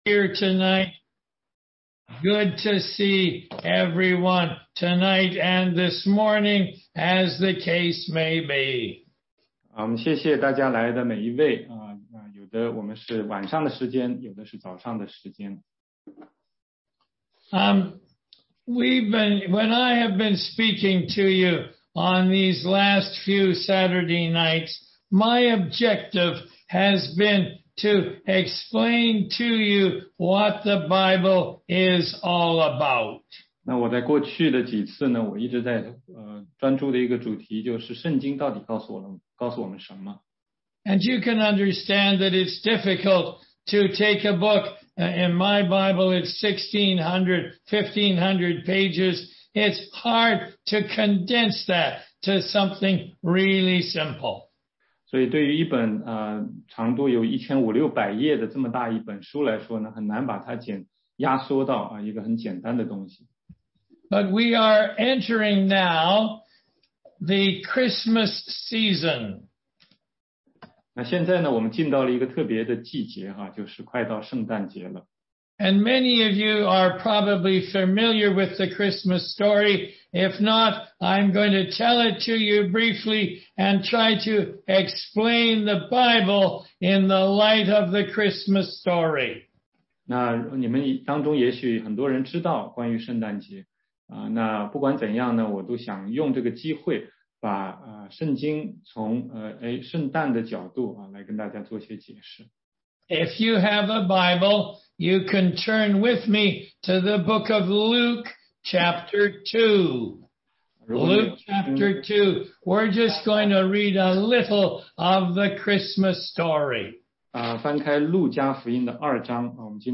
16街讲道录音 - 福音课第八讲